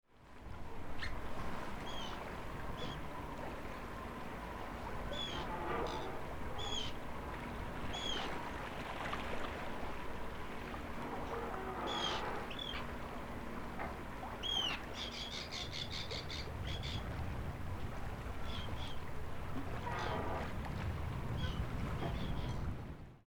PFR04078, 100806, Arctic Tern Sterna arctica, adults+juveniles, calls, North Sea